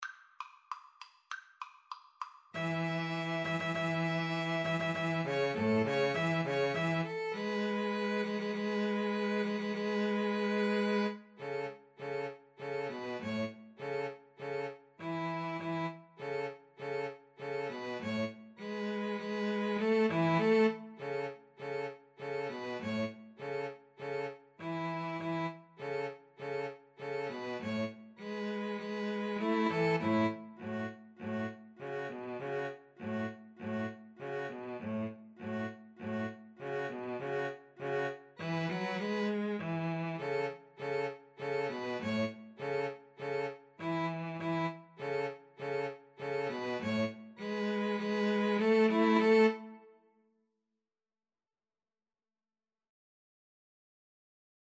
Violin 1Violin 2Cello
Presto =200 (View more music marked Presto)
Classical (View more Classical 2-Violins-Cello Music)